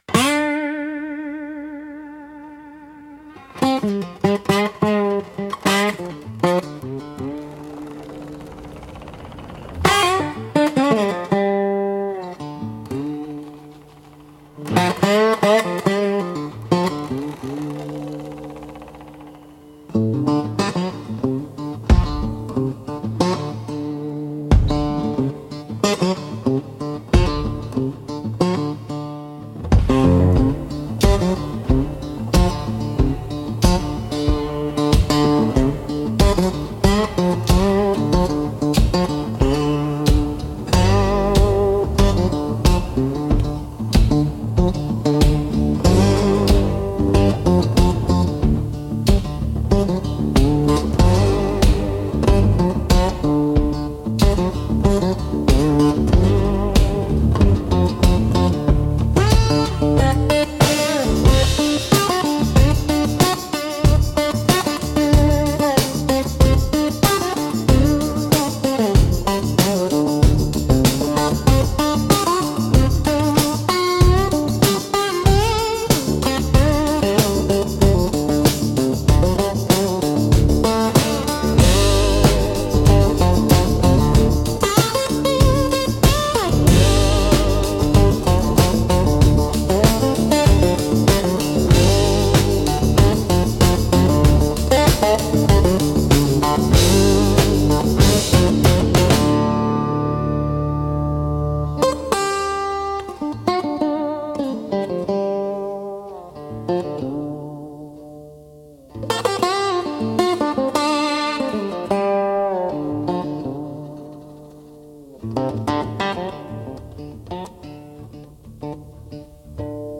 Instrumental - Bottleneck Fever 3.39